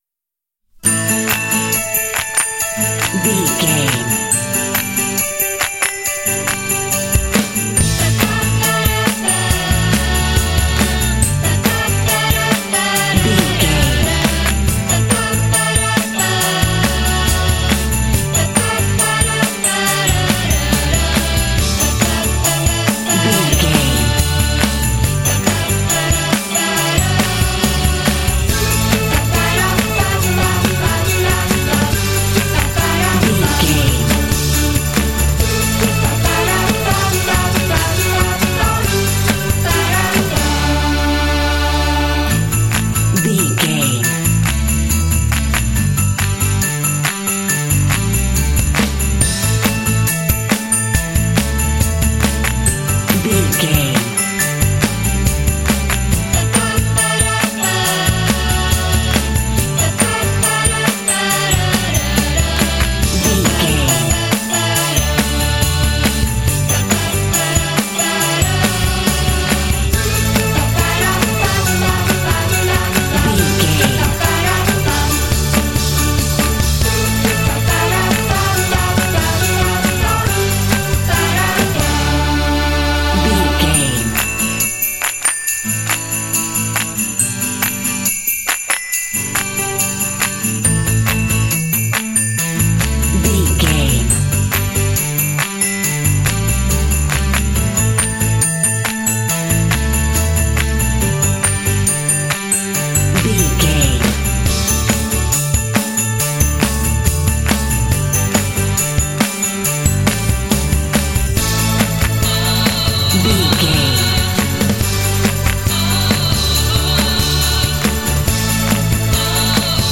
Ionian/Major
bouncy
energetic
cheerful/happy
drums
vocals
bass guitar
electric guitar
pop
rock